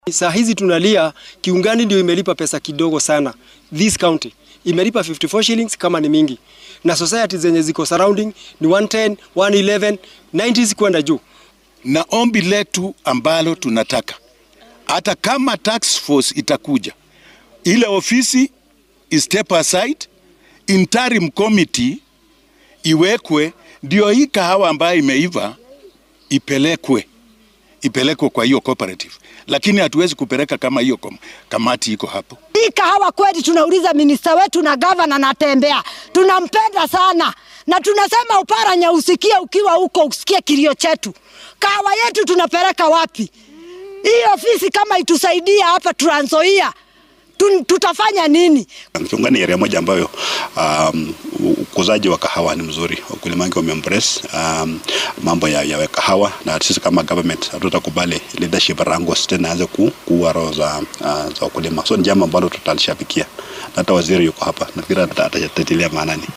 Beeralayda soo saaro Qaxwada ee aagga Kiungani ee ismaamulka Trans Nzoia ayaa sameeyay dibadbax. Waxay doonayaan in dowladdu ay xalliso muran hoggaamineed oo saameeyay shirkadda Qaxwada oo ay sheegeen inuu soo jiray muddo 29 sano ah. Qaar ka mid ah beeralayda iyo barasaabka Trans Nzoia George Natembeya oo warbaahinta la hadlay ayaa yiri.